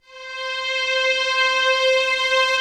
Index of /90_sSampleCDs/Optical Media International - Sonic Images Library/SI1_Swell String/SI1_Soft Swell